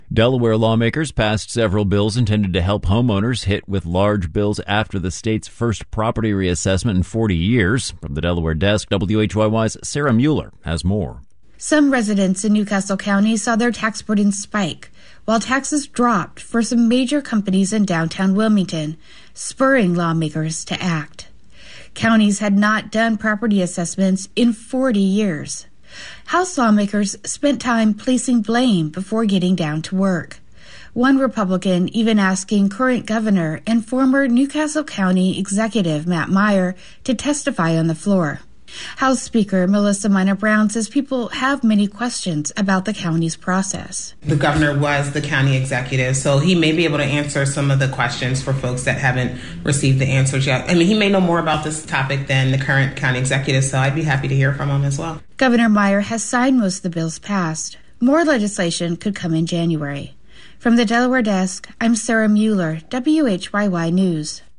Former Philadelphia Mayor John Street has kept a low profile since leaving office in 2008, but, in a rare appearance, this week he gave a spirited defense of indicted U.S. Rep. Chaka Fattah.
The standing-room-only crowd of elected officials and community activists from North Philly seemed glad to see the former mayor.